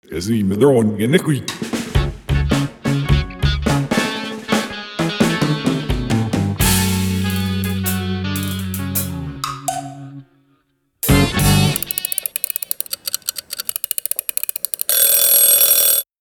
The music was recorded in my home studio. I play the drums, bass, synthesizer and guitar on this short piece. I also recorded the sounds of a household timer being wound and ringing out...